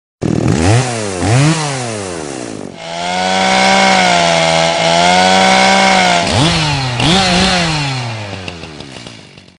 Chainsaw Sound
Chainsaw — intense buzzing sound effect of a chainsaw, perfect for horror memes, action edits, or loud moments.